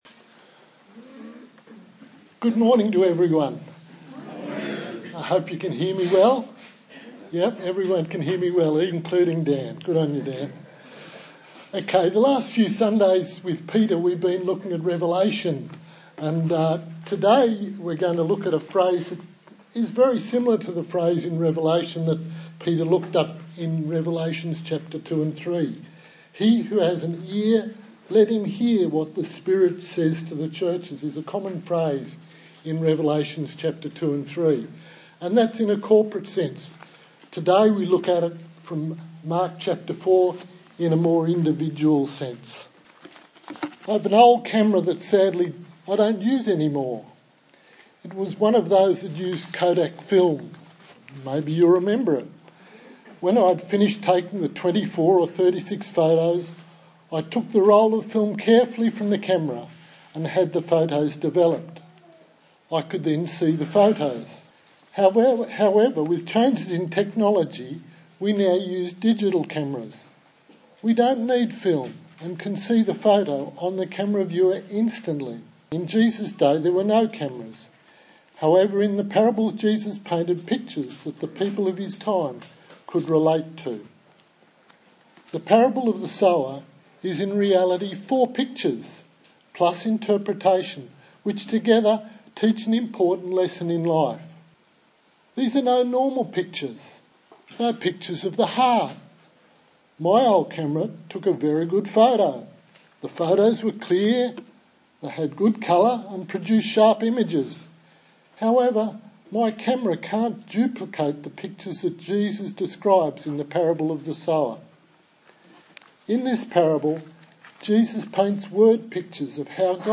Mark Passage: Mark 4:1-20 Service Type: Sunday Morning